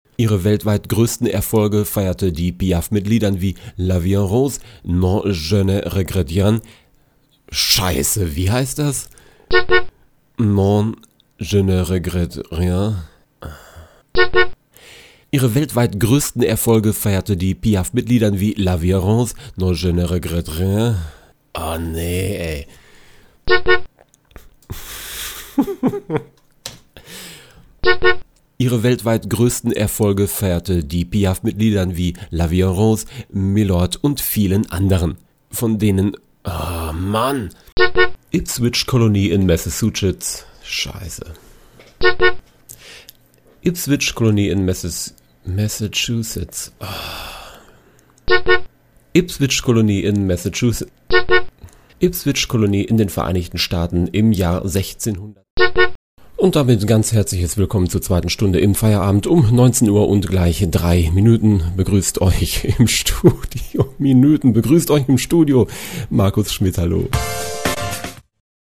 Voll daneben: Outtakes.